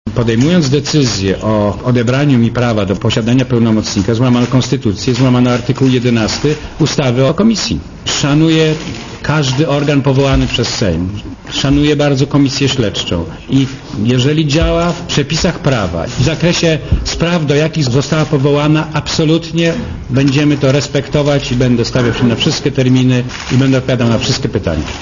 Posłuchaj Jana Kulczyka
kulczykkonferencja.mp3